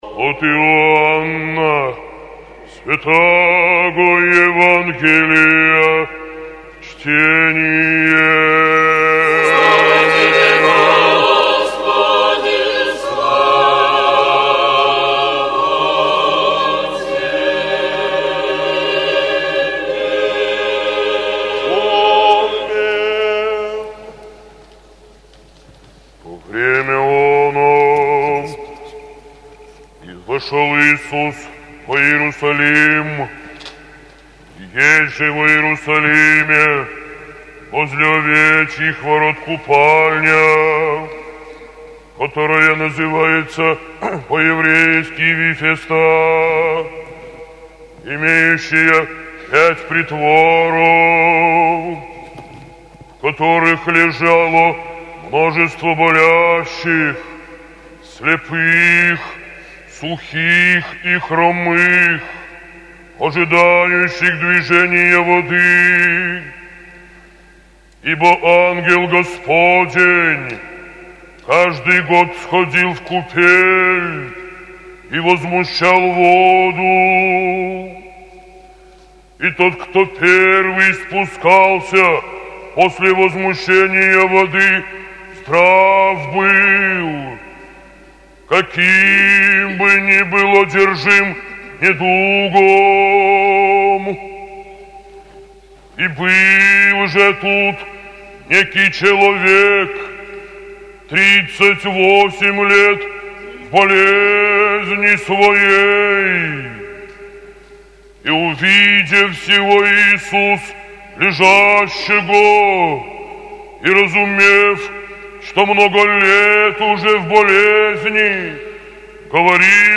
ЕВАНГЕЛЬСКОЕ ЧТЕНИЕ НА литургии